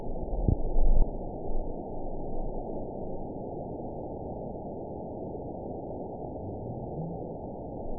event 910712 date 01/30/22 time 03:56:37 GMT (3 years, 3 months ago) score 9.54 location TSS-AB02 detected by nrw target species NRW annotations +NRW Spectrogram: Frequency (kHz) vs. Time (s) audio not available .wav